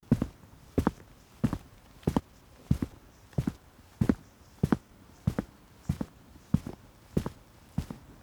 SFX_Footsteps_Stone_01.wav